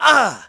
Riheet-Vox_Damage_01.wav